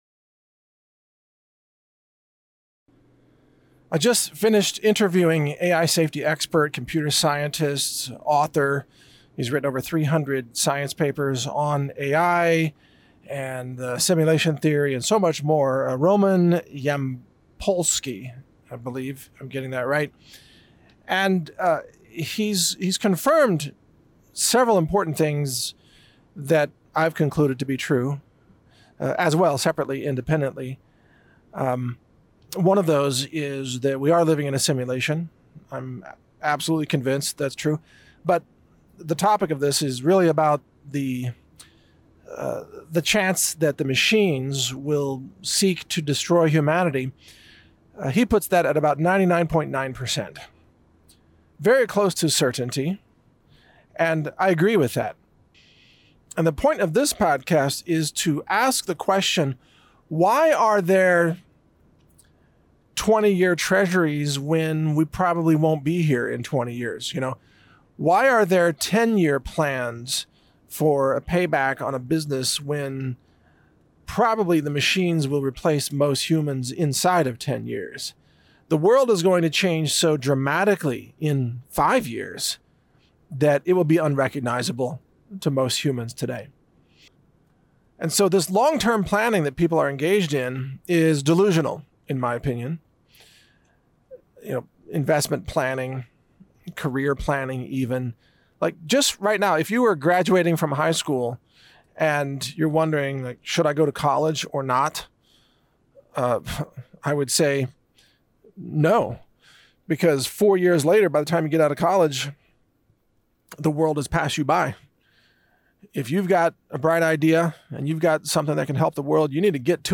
- Interview with AI Safety Expert and Simulation Theory (0:01)